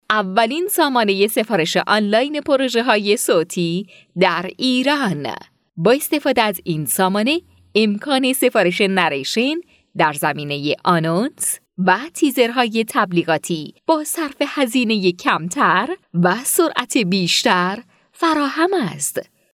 Female
Young
Commercial
Informative